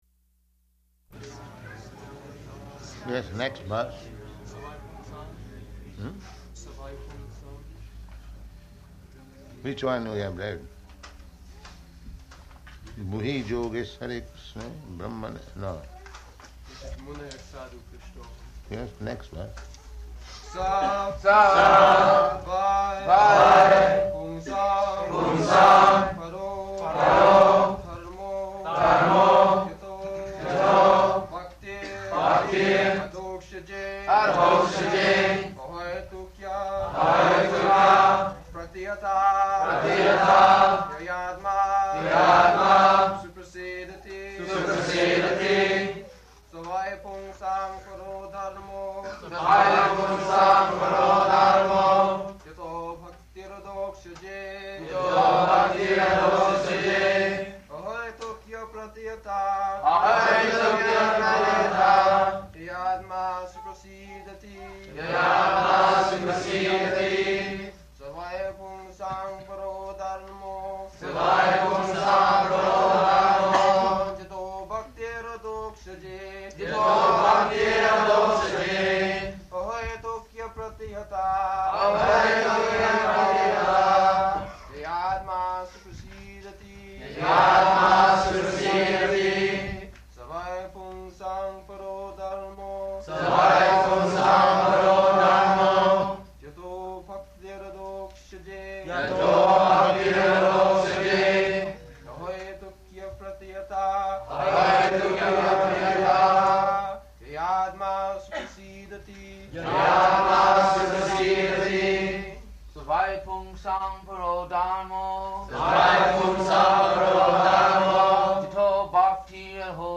Śrīmad-Bhāgavatam 1.2.6 --:-- --:-- Type: Srimad-Bhagavatam Dated: September 4th 1972 Location: New Vrindavan Audio file: 720904SB.NV.mp3 Prabhupāda: Yes, next verse.